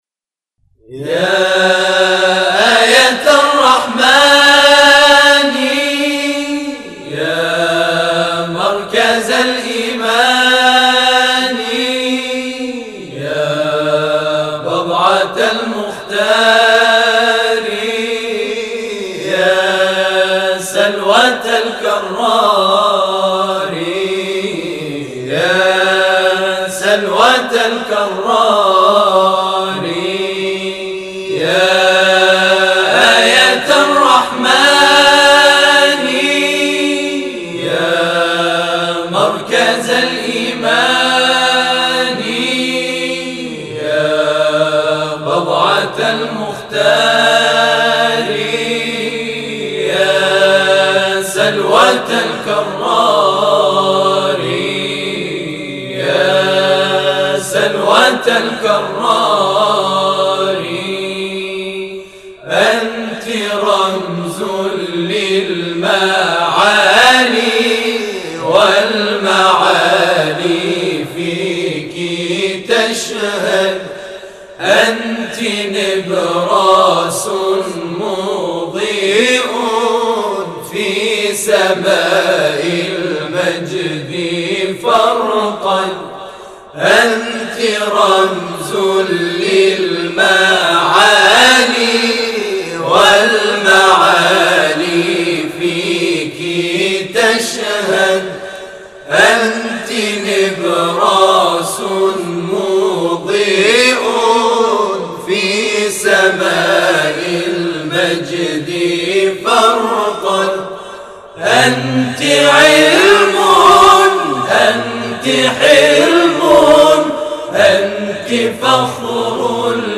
تواشیح
گروه تواشیح سبطین اهواز